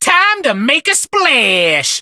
buzz_start_vo_03.ogg